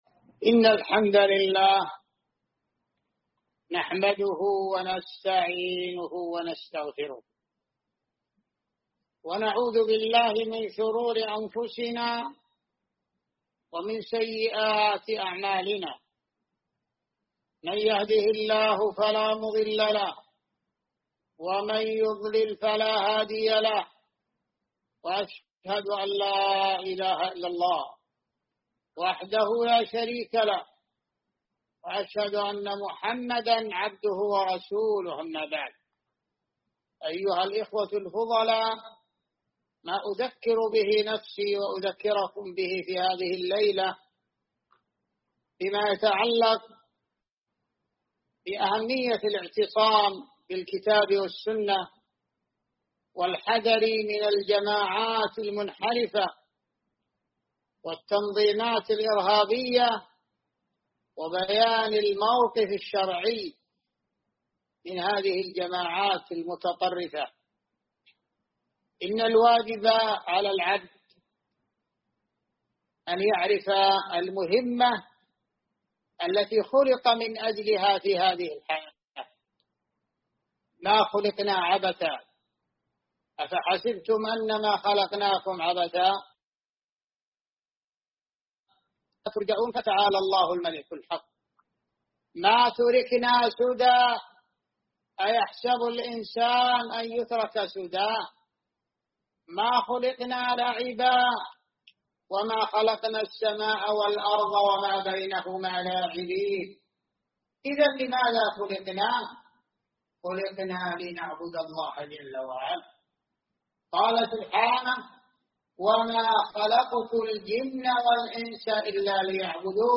محاضرة منهج المسلم في التعامل مع الأحزاب والجماعات
مسجد جابر علي بالركوبة